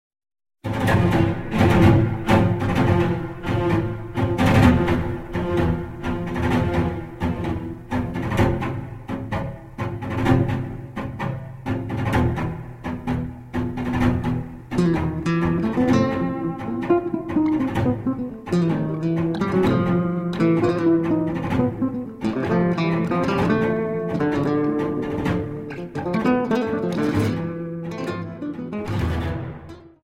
Dance: Tango 32